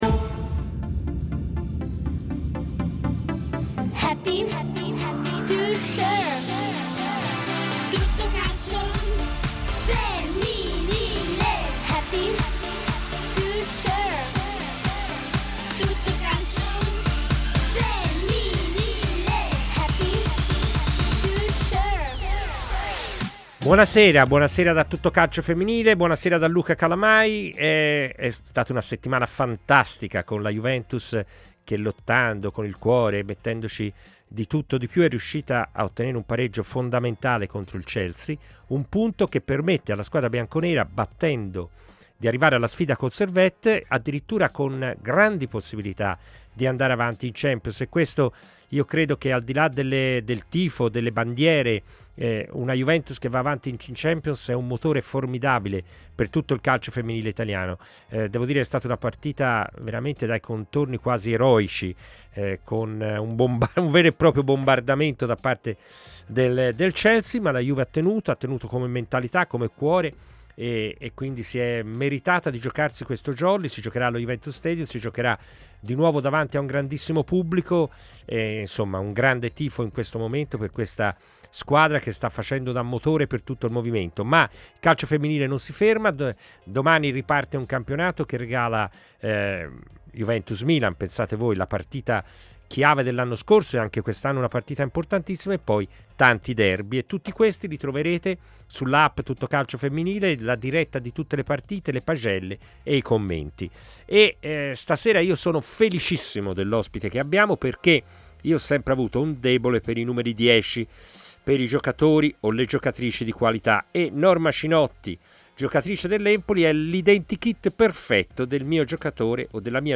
Ospiti telefonici